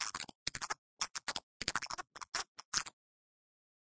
Creature_Sounds-Medium_Rat.ogg